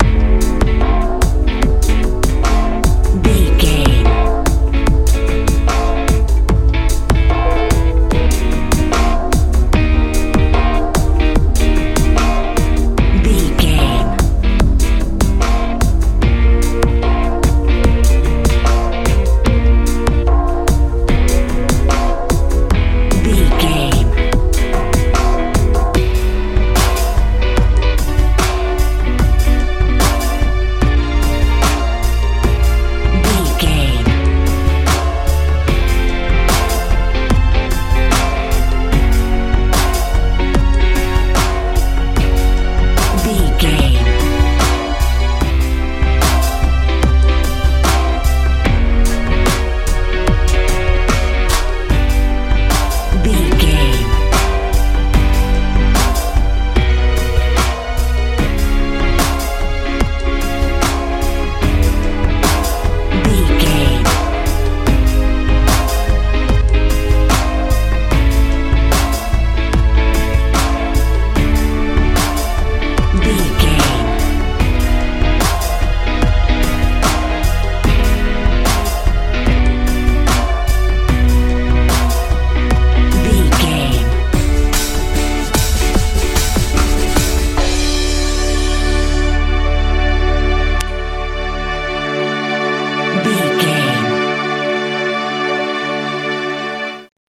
modern pop feel
Aeolian/Minor
strange
suspense
synthesiser
electric guitar
bass guitar
drums
80s
90s
anxious
contemplative
dark